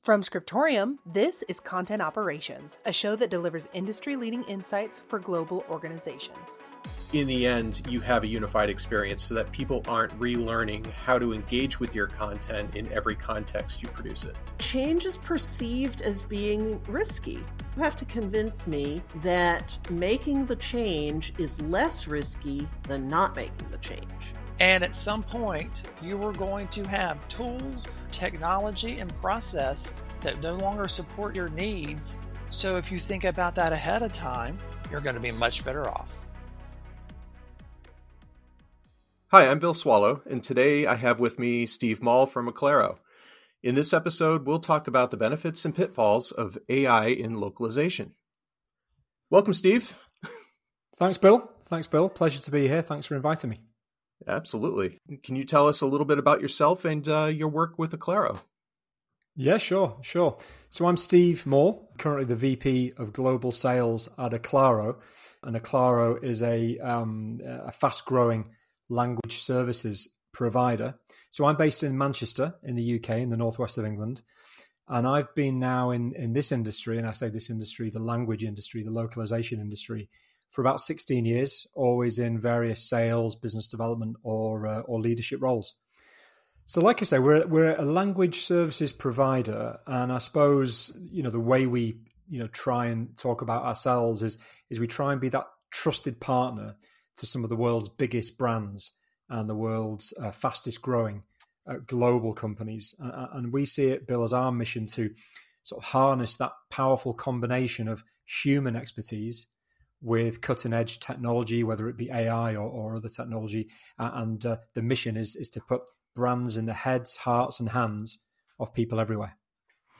Introduction with ambient background music